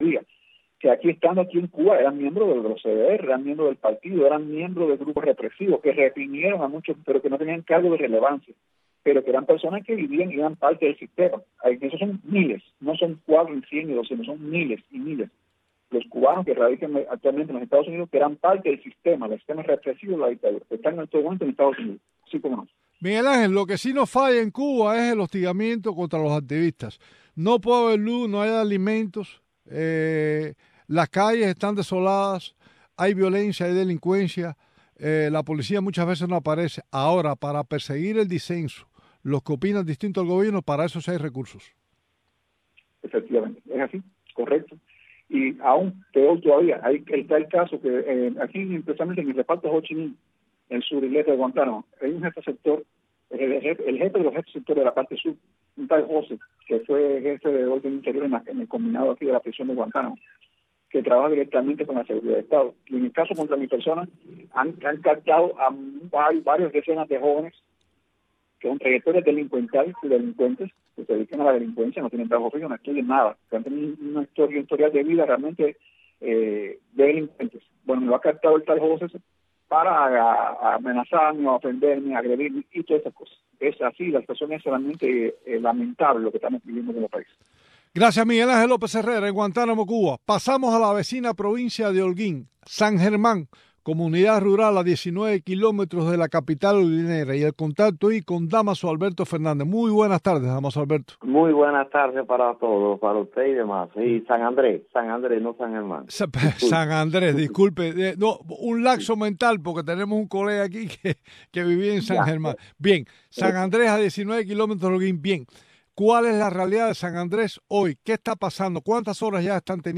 espacio informativo en vivo